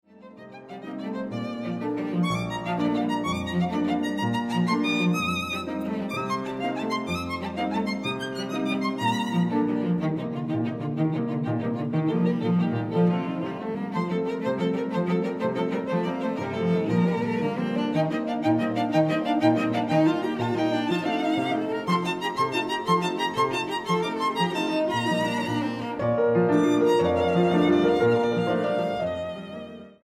Grabado del 2 al 6 de Septiembre de 2013, Sala Xochipilli
Piano: Bechstein